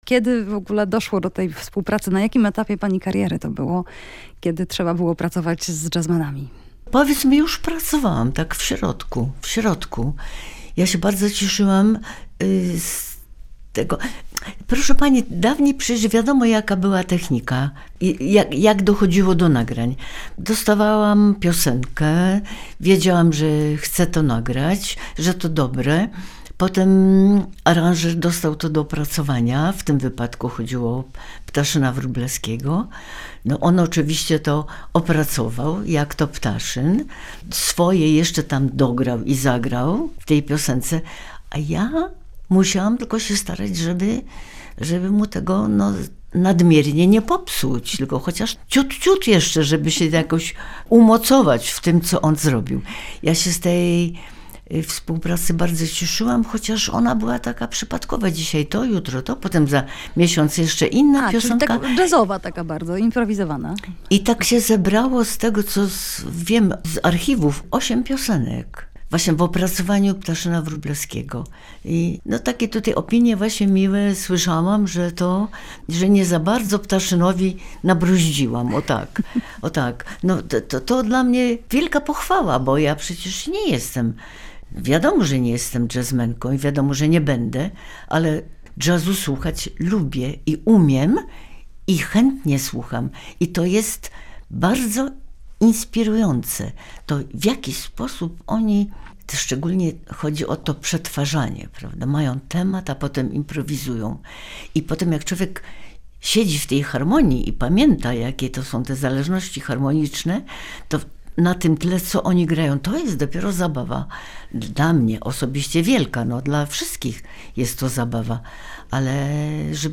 Jubileusz Ireny Santor [POSŁUCHAJ ROZMOWY]